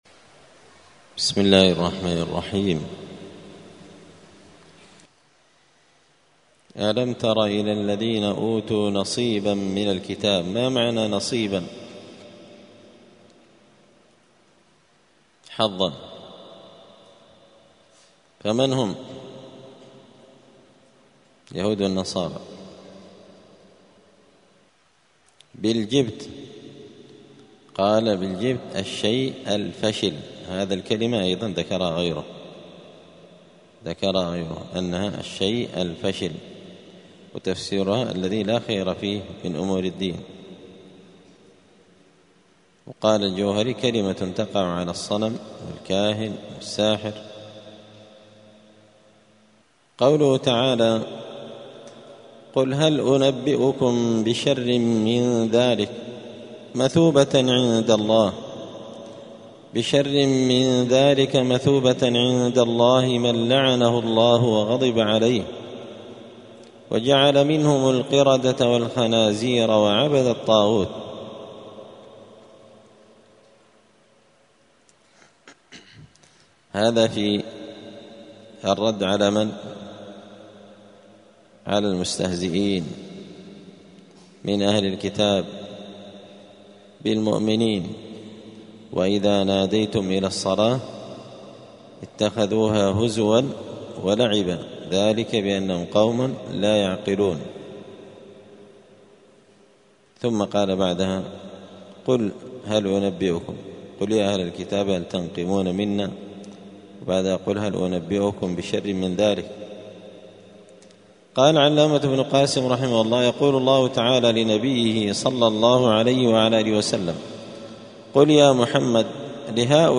دار الحديث السلفية بمسجد الفرقان قشن المهرة اليمن
*الدرس الرابع والستون (64) {باب ماجاء أن بعض هذه الأمة يعبد الأوثان}*